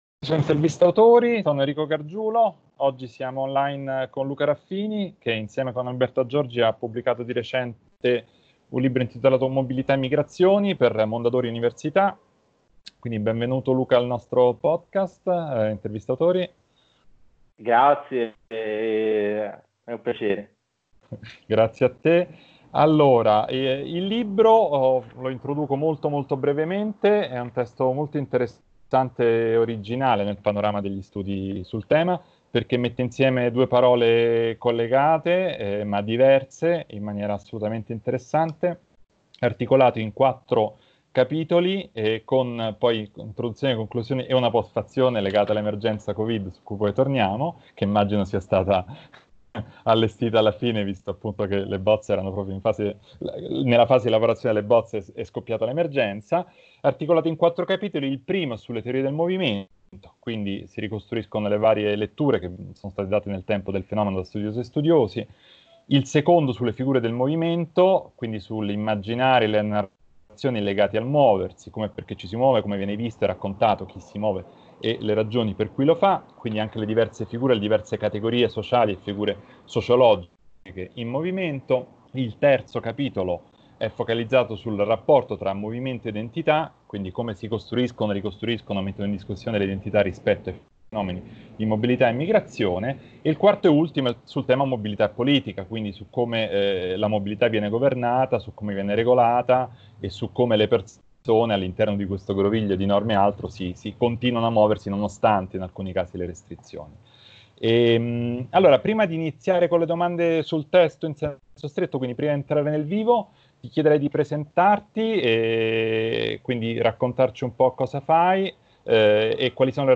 skype-intervistautori.mp3